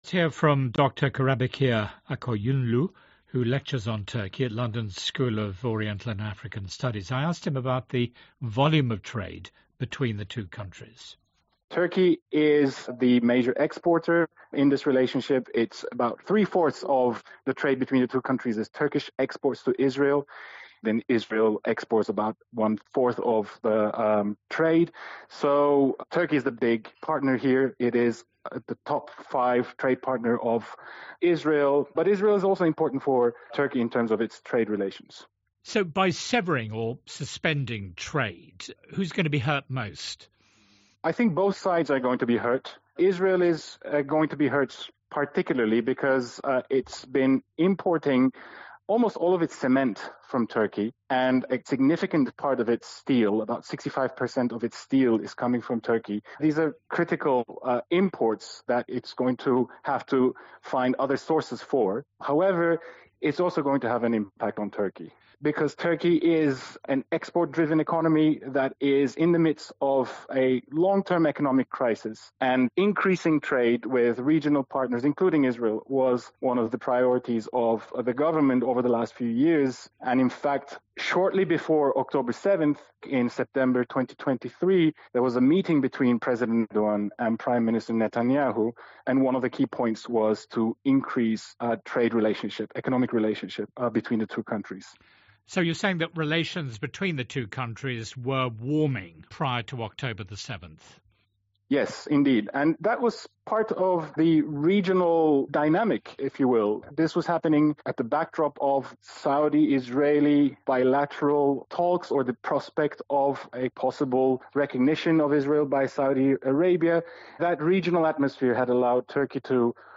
Interview on BBC Newshour onTurkey's decision to severe trade ties with Israel (3 May)